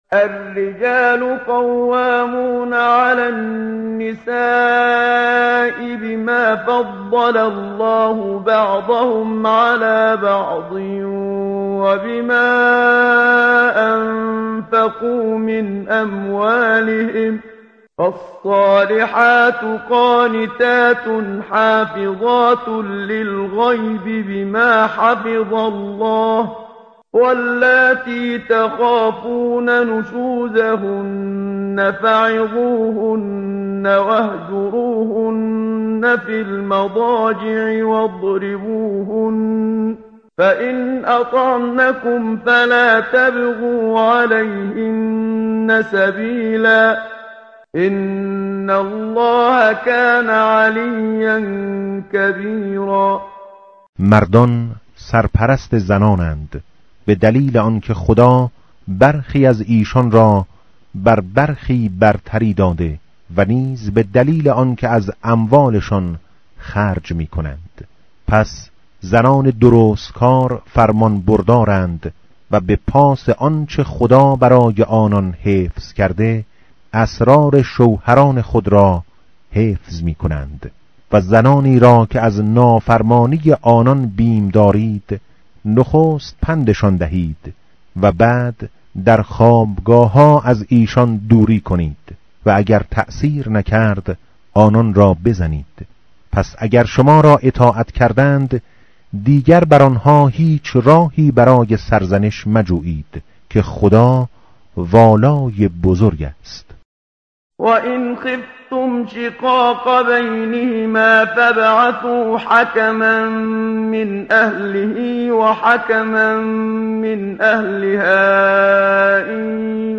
tartil_menshavi va tarjome_Page_084.mp3